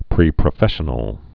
(prēprə-fĕshə-nəl)